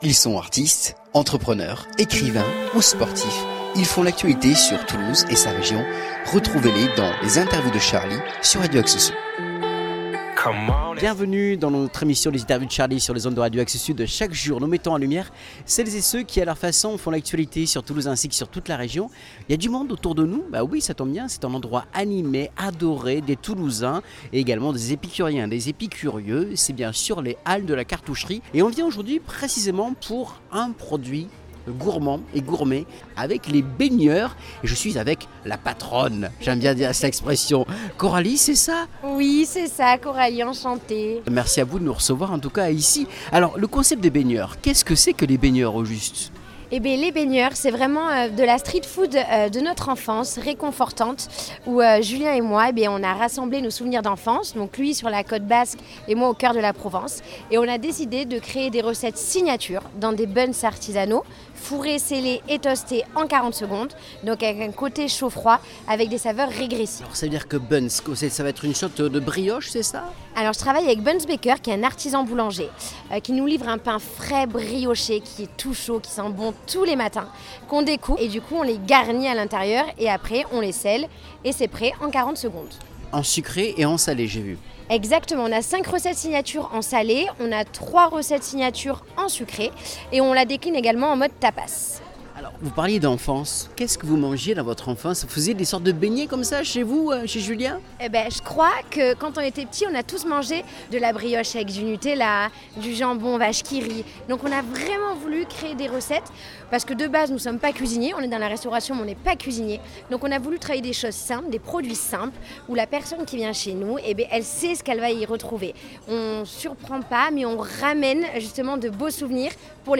Au Micro d’Axe Sud qui nous en dit davantage avec cette nouvelle enseigne à retrouver aux halles de la Cartoucherie à Toulouse.